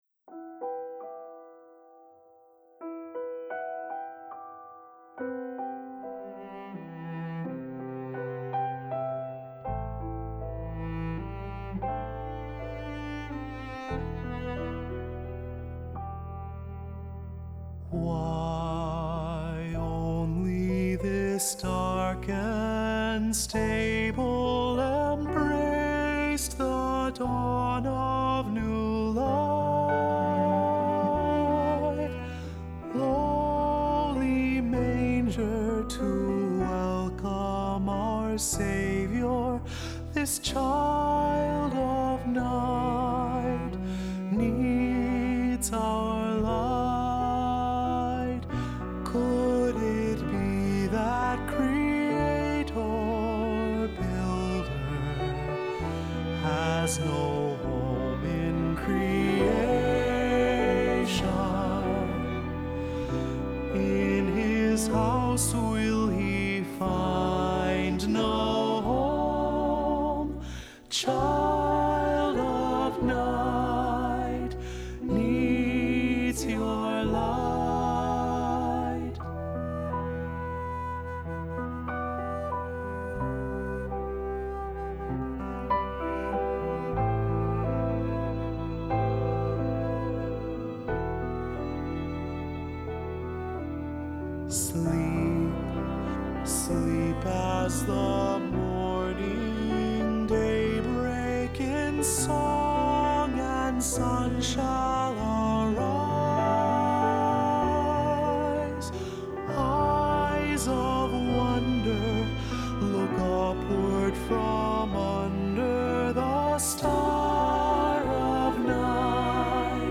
Accompaniment:      Keyboard
Music Category:      Choral